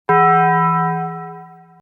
sfx update
Taco_Bell_Bong.ogg